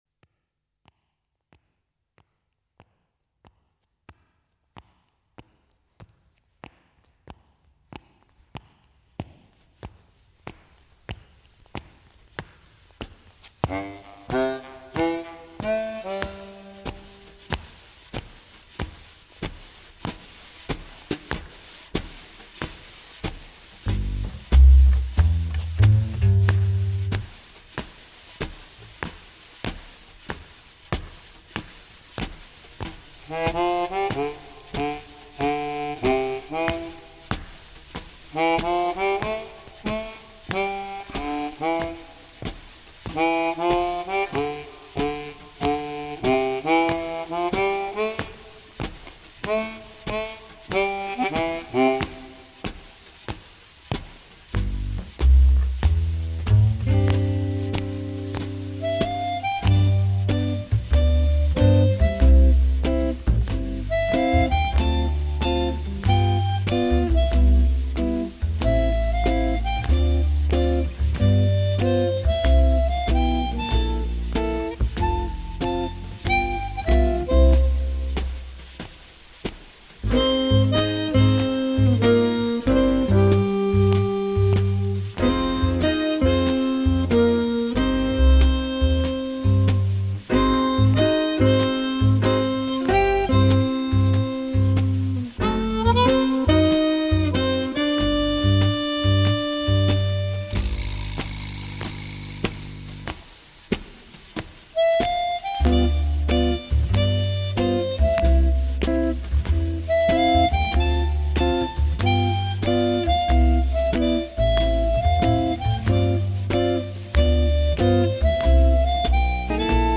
[2/23/2008]慵懒的抒情曲--漫步 激动社区，陪你一起慢慢变老！